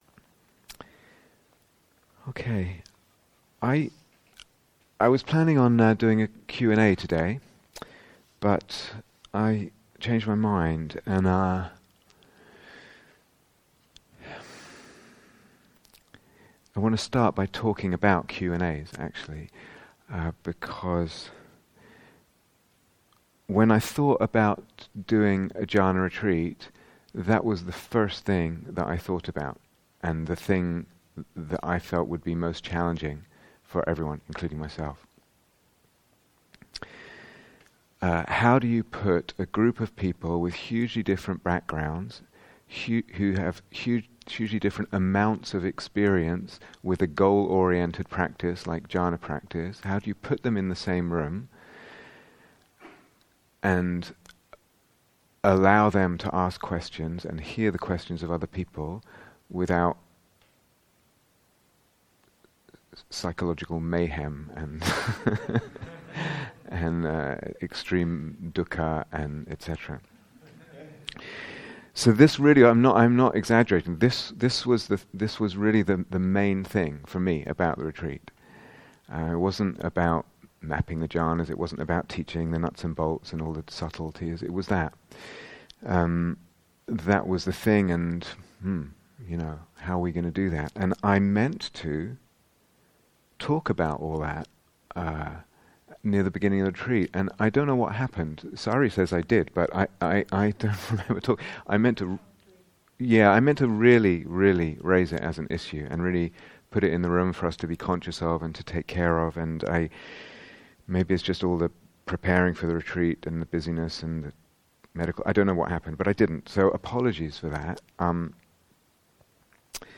True to Your Deepest Desires (Talk and Short Guided Meditation)